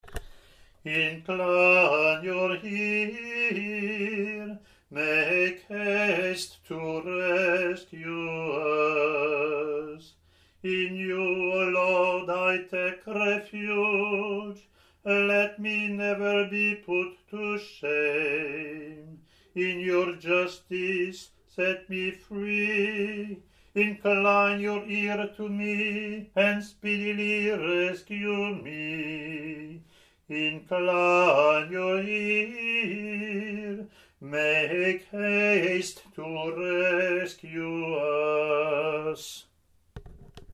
ot13-comm-eng-lm.mp3